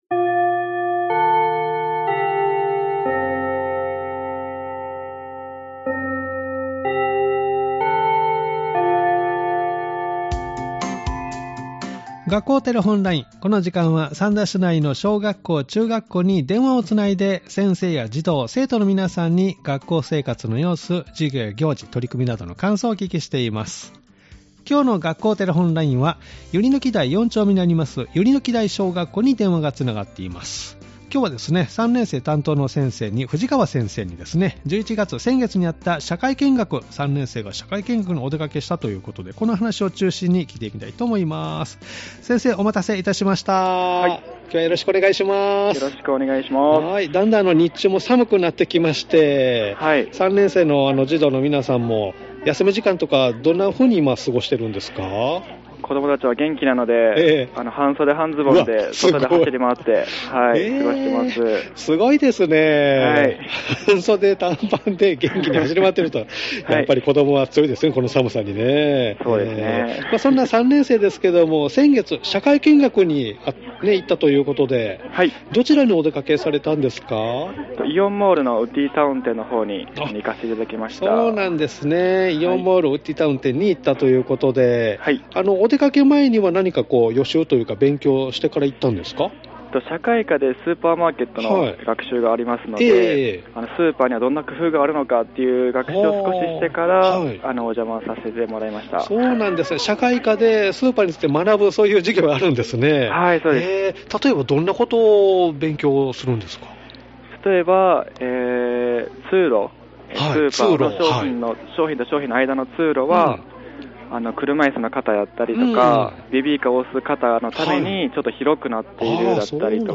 「学校テレフォンライン」では三田市内の小学校、中学校に電話をつないで、先生や児童・生徒の皆さんに、学校生活の様子、授業や行事、取り組みなどの感想をお聞きしています！